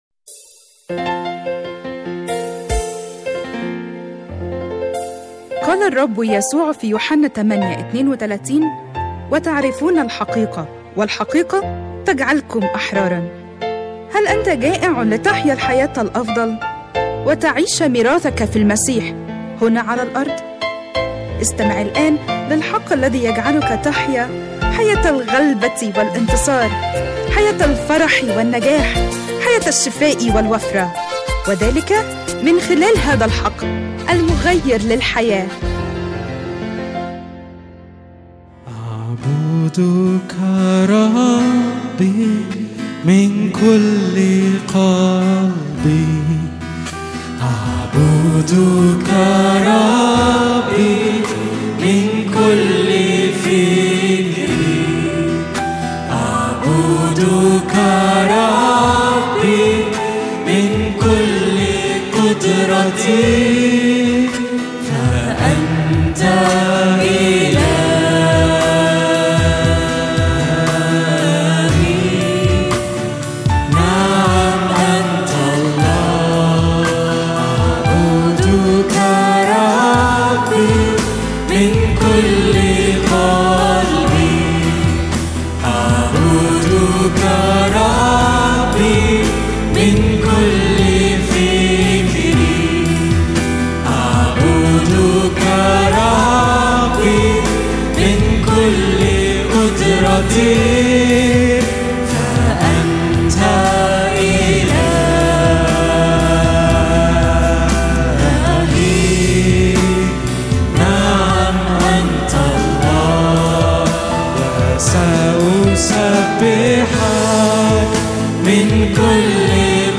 🎧 Download Audio 1 تحميل اجتماع الثلاثاء 27/1/2026 لسماع العظة على الساوند كلاود أضغط هنا لمشاهدة العظة على اليوتيوب ـ من تأليف وإعداد وجمع خدمة الحق المغير للحياة وجميع الحقوق محفوظة.